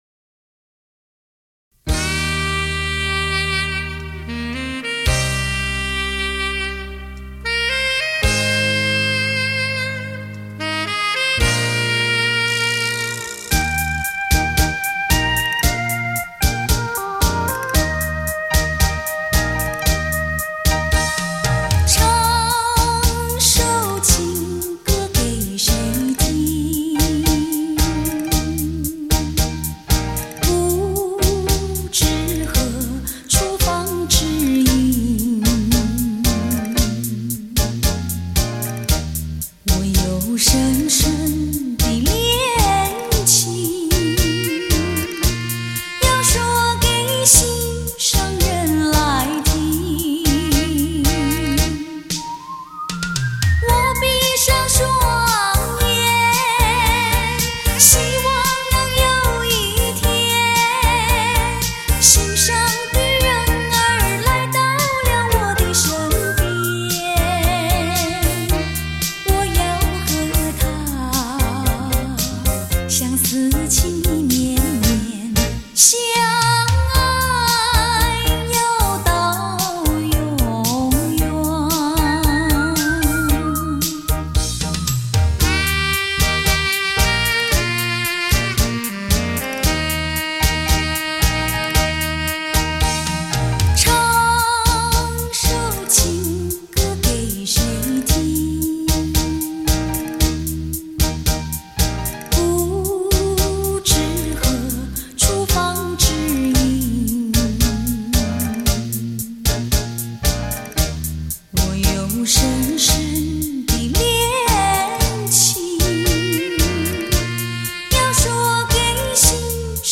一幕漂亮的舞曲 如一场欲拒还迎的爱情角力 让你陶醉其中
缠绵激情的舞姿 而又不失情深款款